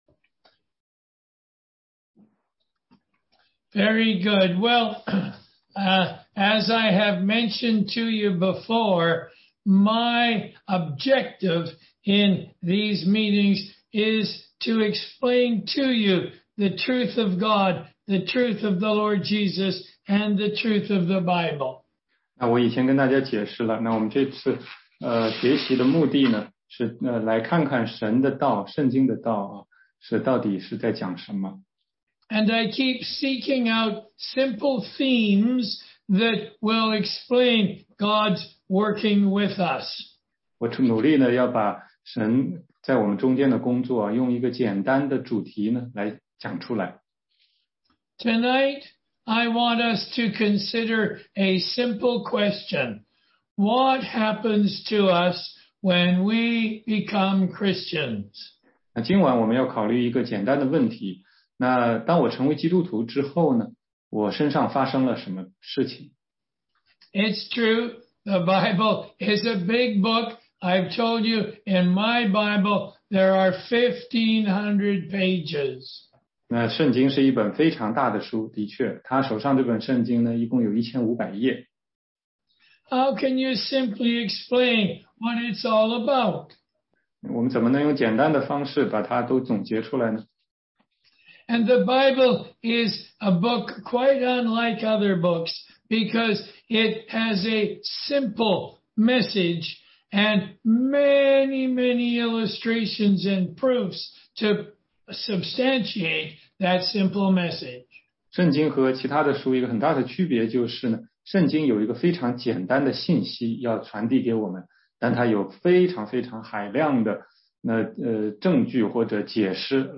16街讲道录音 - 福音基础
福音课第三十三讲.mp3